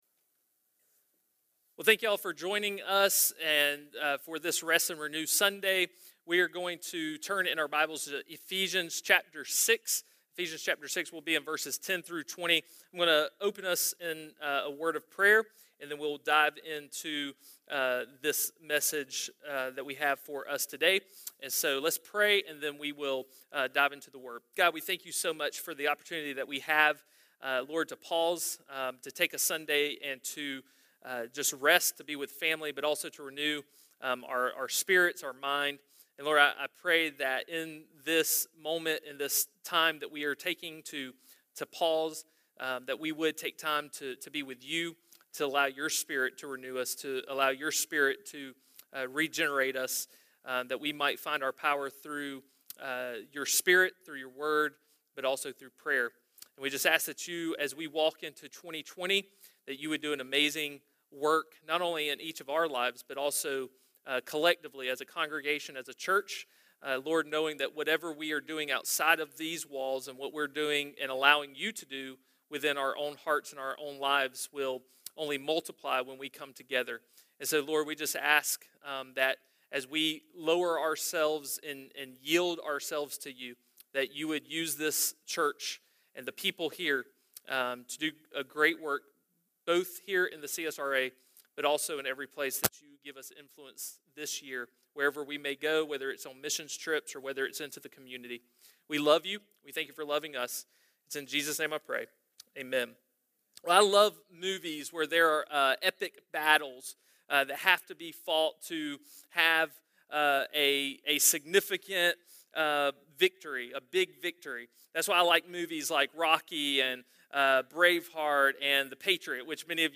A message from the series "No Rival."